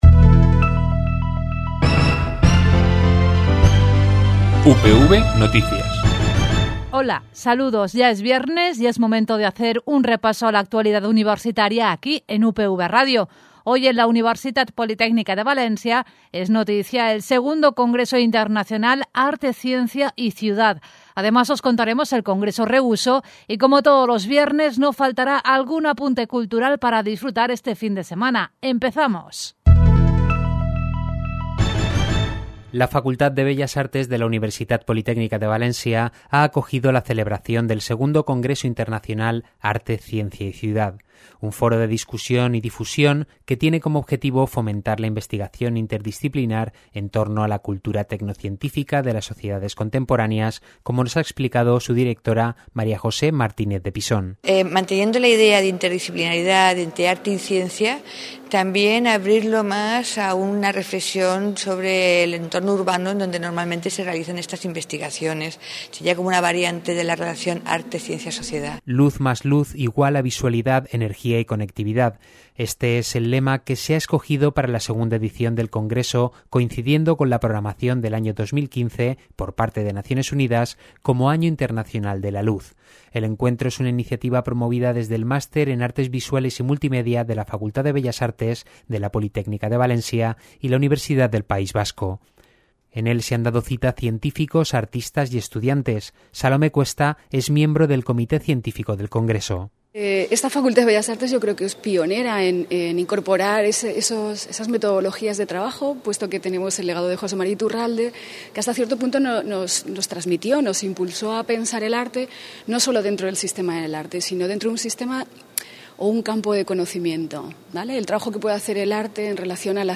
Radio � Informativo UPV